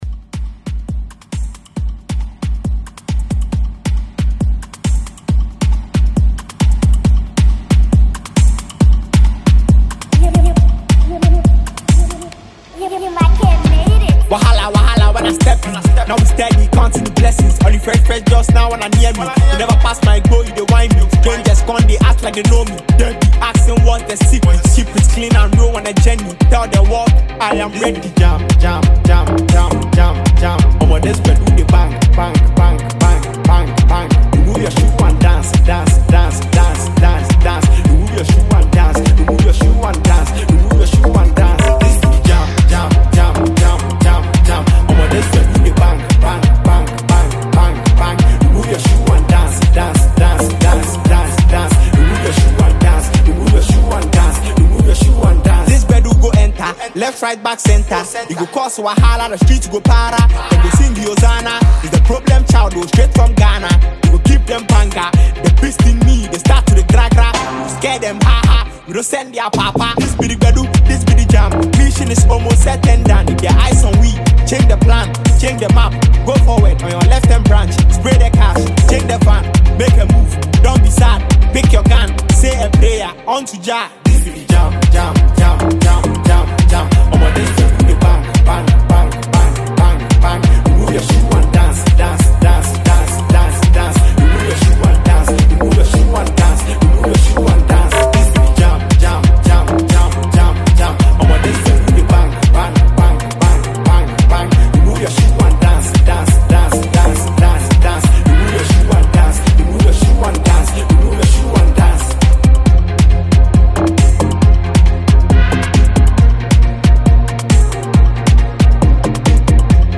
Enjoy this dope rap production.